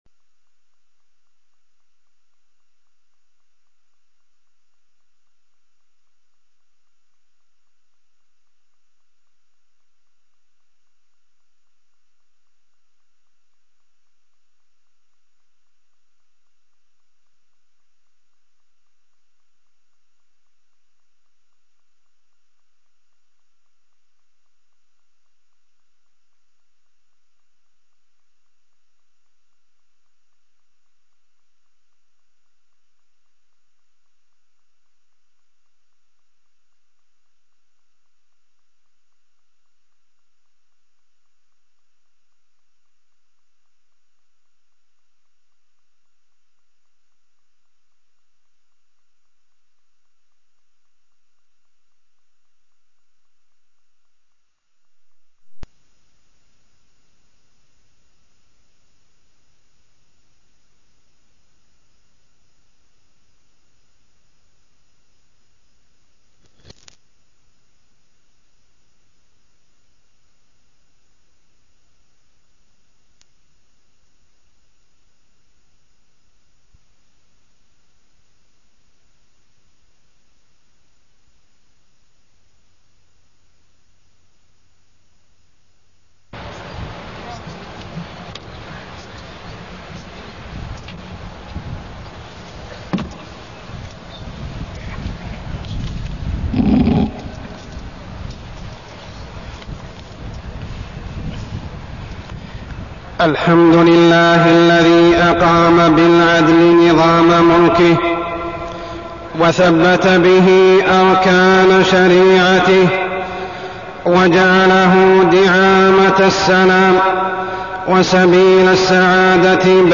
تاريخ النشر ٢١ محرم ١٤١٧ هـ المكان: المسجد الحرام الشيخ: عمر السبيل عمر السبيل أساس العدل